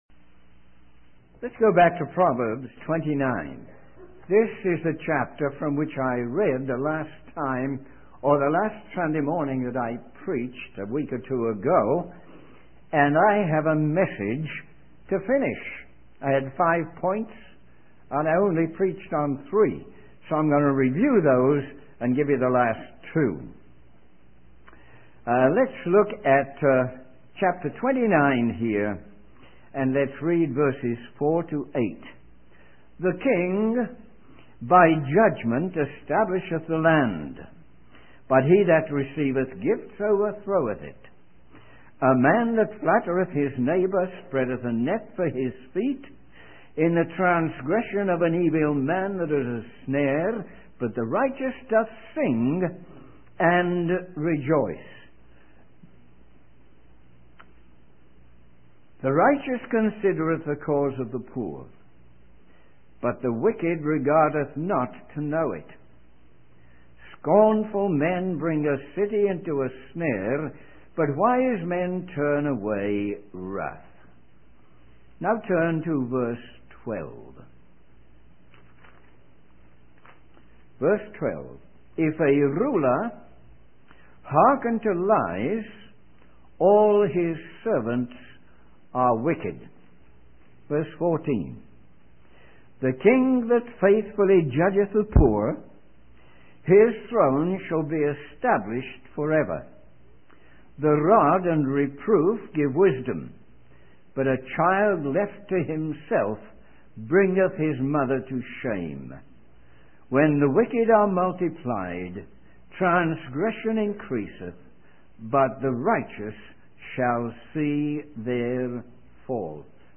In this sermon, the preacher focuses on Proverbs 29 and discusses the importance of standing up for principles and doing what is right, rather than what is convenient. He highlights the contrast between the righteous and the wicked, emphasizing the righteous person's consideration for the poor and the wicked person's disregard for them.